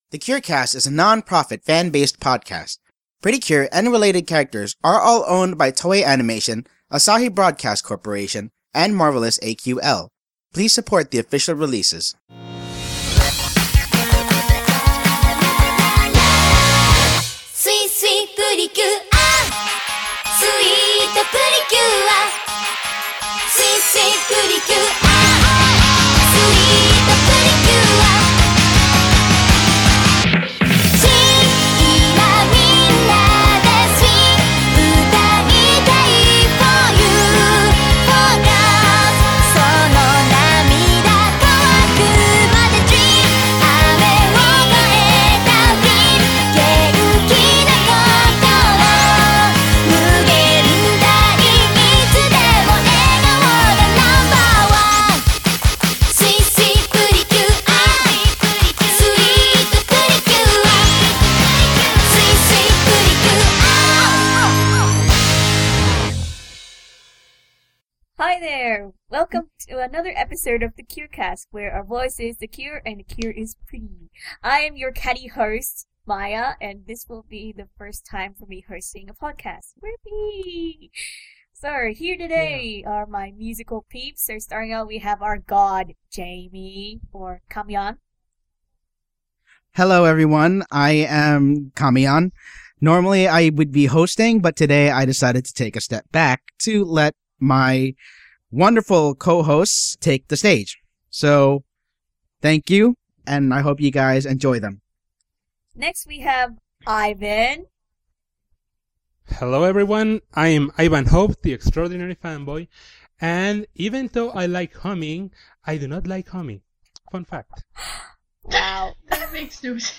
*We do not actually sing, our voices are horrible.